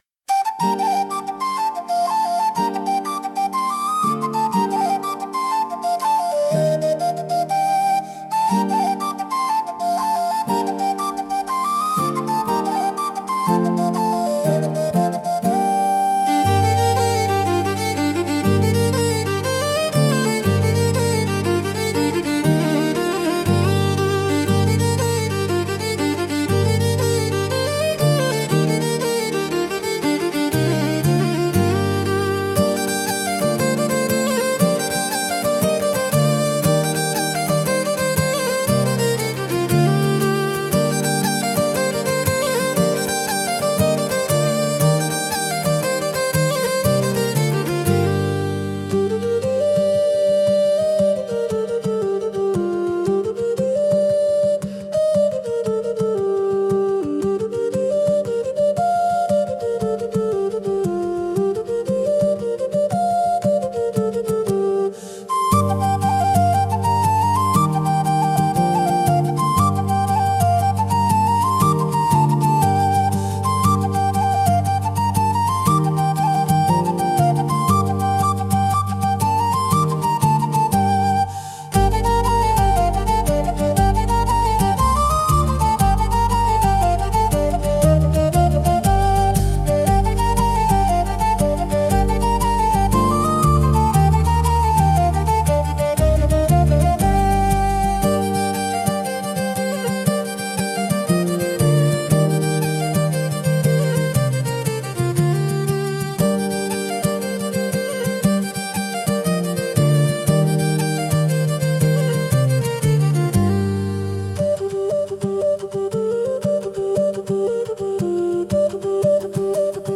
聴く人に親しみやすさと爽やかな感動を届ける民族的で情緒豊かなジャンルです。